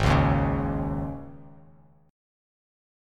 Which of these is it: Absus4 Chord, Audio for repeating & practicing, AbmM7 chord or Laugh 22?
Absus4 Chord